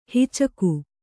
♪ hicaku